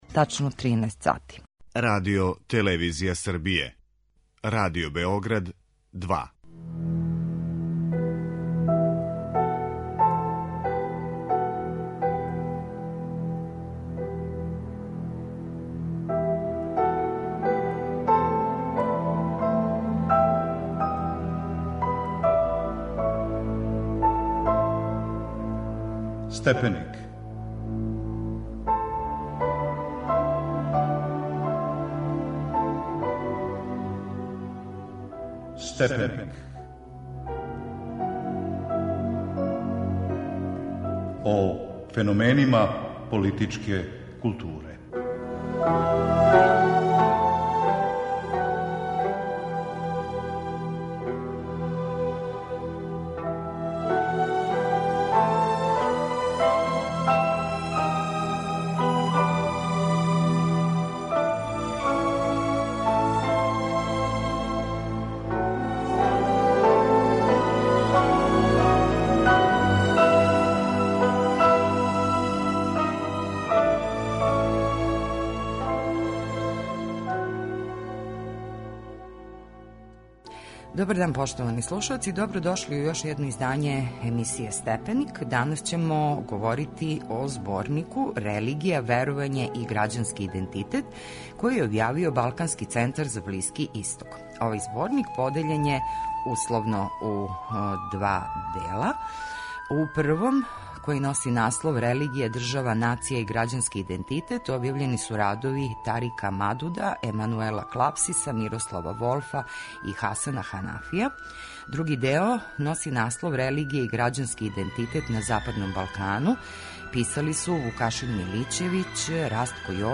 Емисија истражује класичне, модерне, постмодерне политичке идеје. [ детаљније ] Све епизоде серијала Аудио подкаст Радио Београд 2 Лагани ставови из Малерових симфонија Концерт Јохана Ванхала - "нешто најближе Моцарту" у литератури за контрабас.